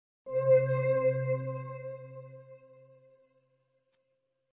Descarga de Sonidos mp3 Gratis: suspenso 3.